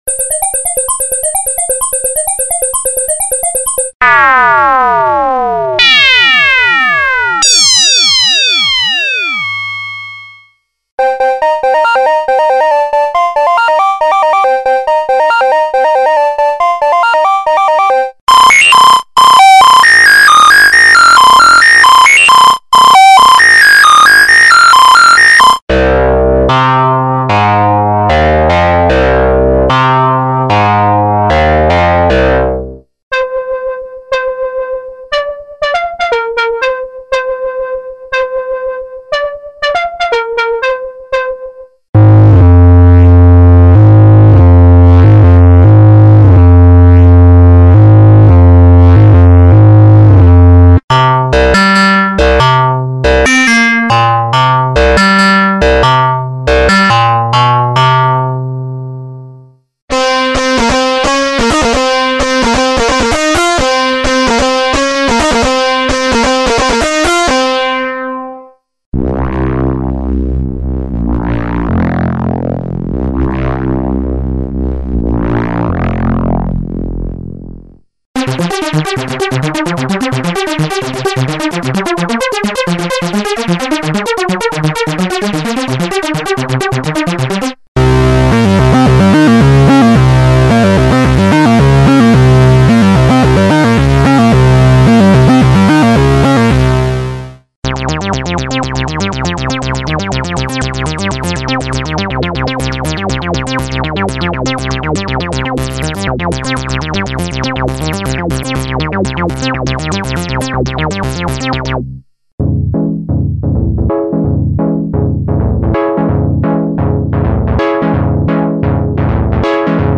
Budget mini-synth that may interest people looking for hybrid-sid like synthesis with controls.
edit VOICE monophonic subtractive synthesis.
filter FILTER analog low-pass VCF at 4 poles, it reaches self-resonance.
demo AUDIO DEMO
demo filter modulator
demo filtering a digital pad
-sid like sound